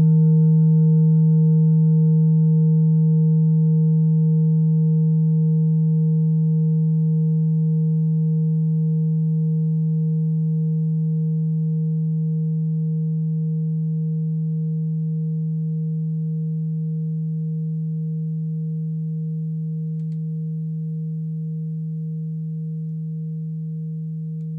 Klangschalen-Typ: Tibet
Klangschale Nr.7
Gewicht = 1160g
Durchmesser = 11,2cm
(Aufgenommen mit dem Filzklöppel/Gummischlegel)
klangschale-set-6-7.wav